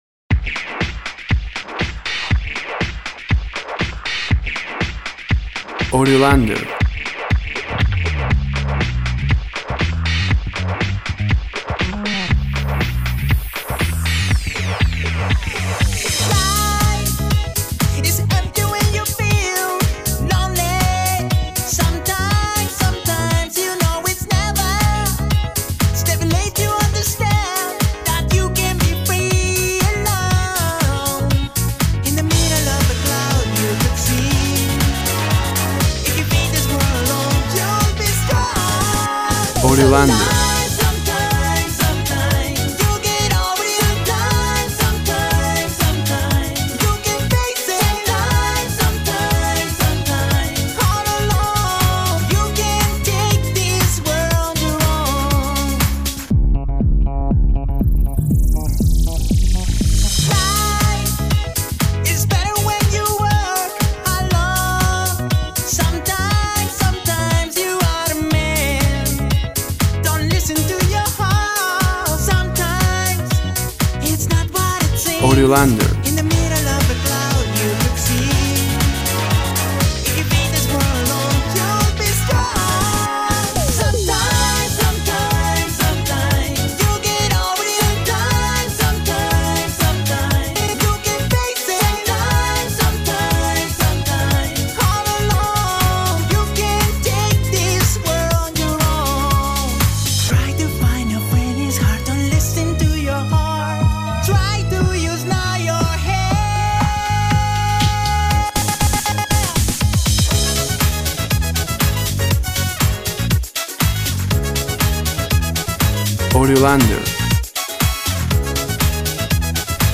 Electro pop disco, happyness,energy,party.
Tempo (BPM) 120